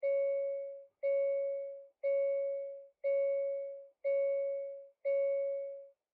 Звуки ремня безопасности
Звук Ремня (Hyundai) (00:06)